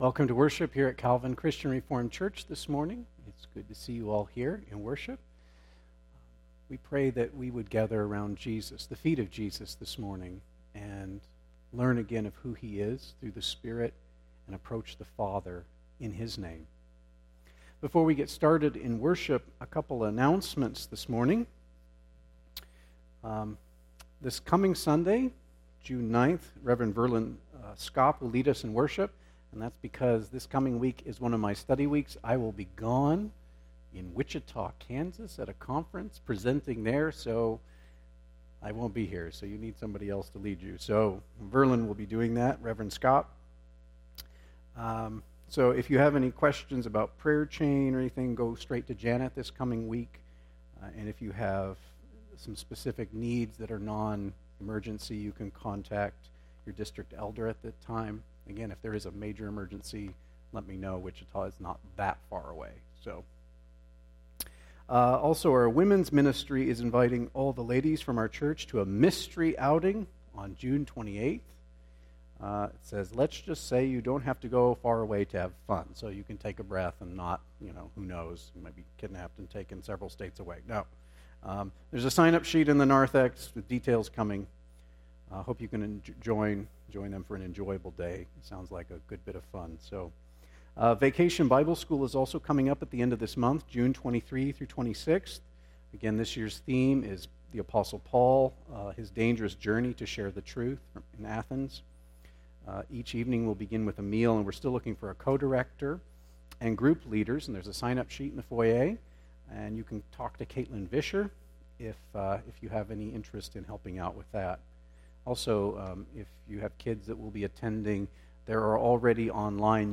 Calvin Christian Reformed Church Sermons